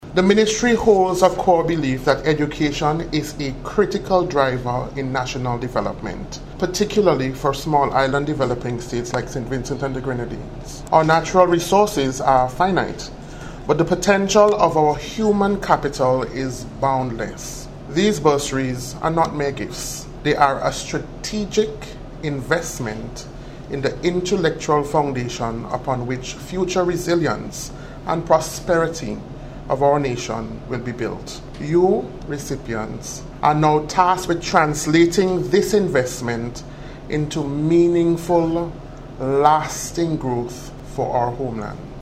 Speaking at yesterday’s GECCU awards ceremony